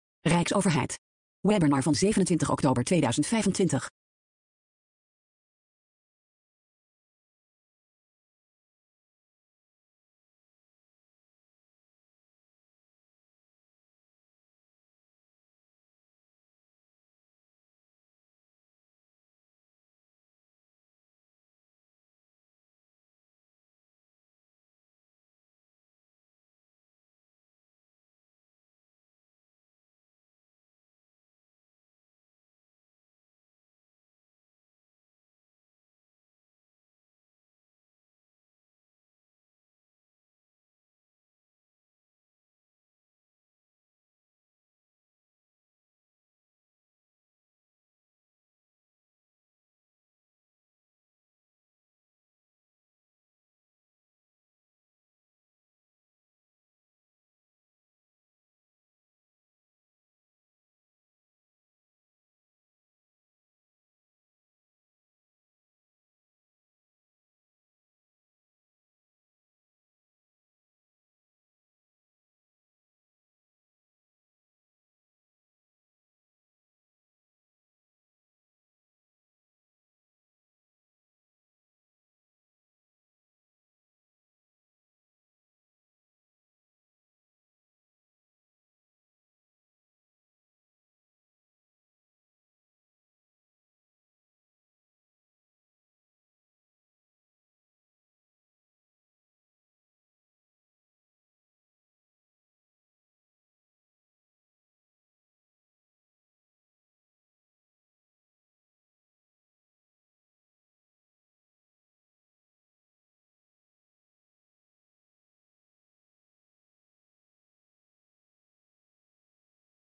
RADIO Webinar: Ethisch kompas bij inzet GenAI | RijksAcademie voor Digitalisering en Informatisering Overheid